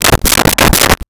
Door Knock 4x
Door Knock 4x.wav